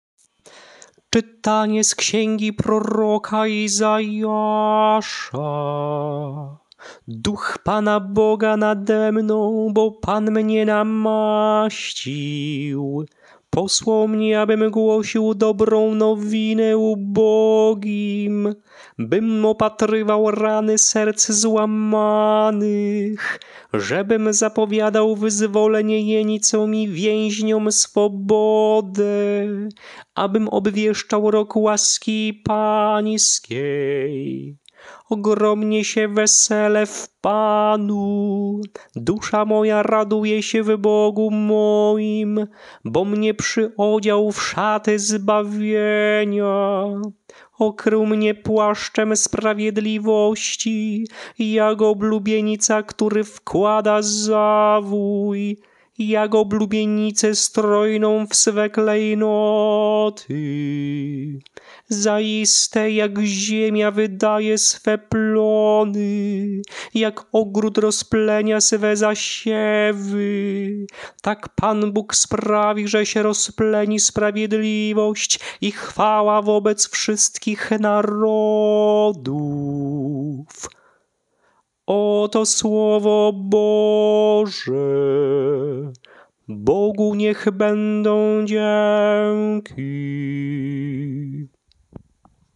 Śpiewane lekcje mszalne – III Niedziela Adwentu